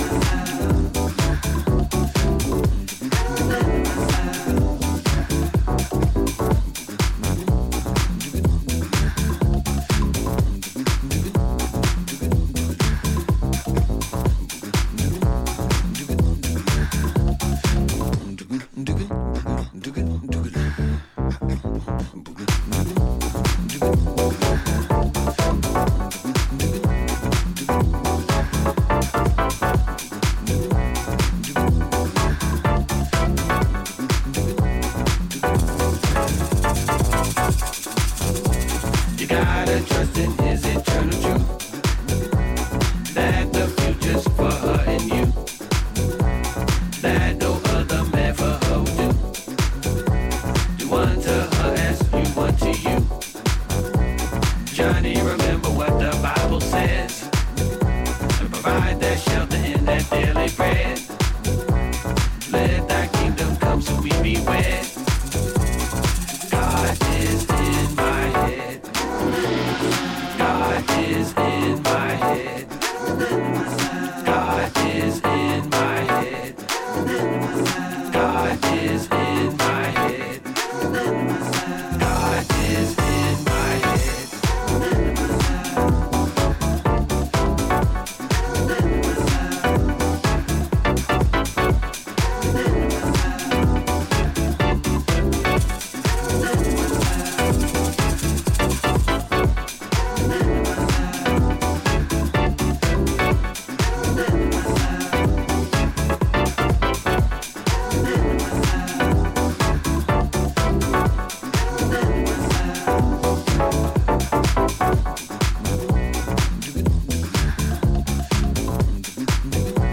a cowbell-packed house stormer